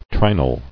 [tri·nal]